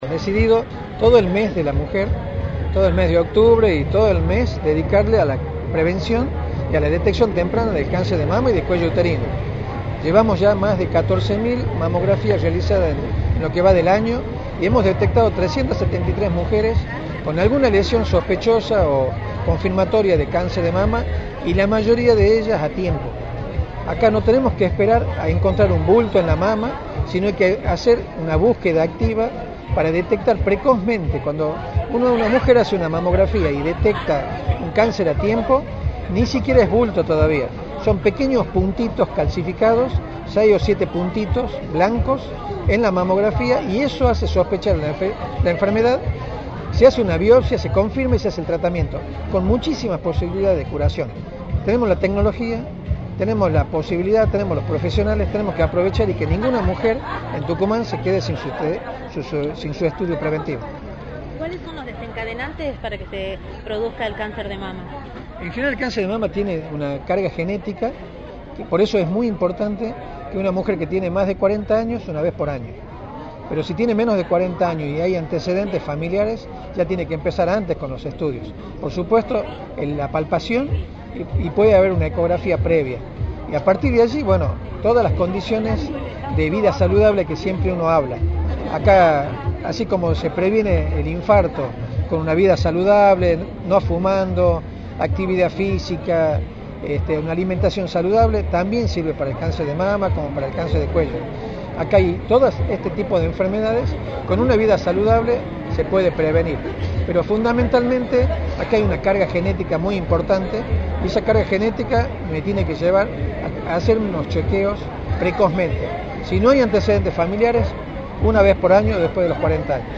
Luís Medina Ruíz, Ministro de Salud, indicó en Radio del Plata Tucumán, por la 93.9, las actividades previstas por el Día de la Lucha contra el Cáncer de Mama.